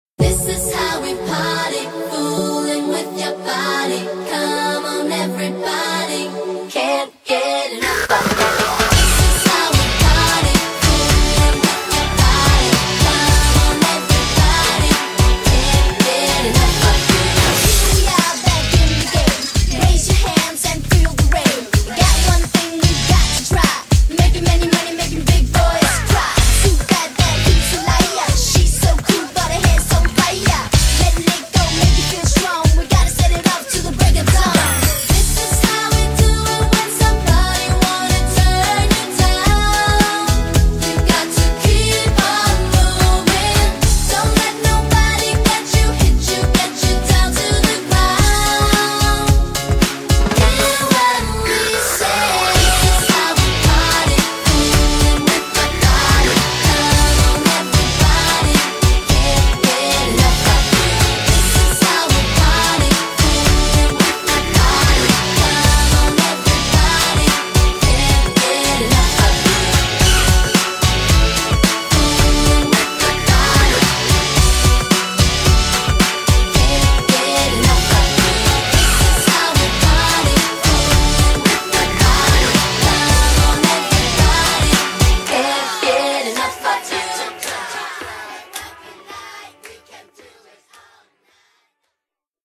BPM110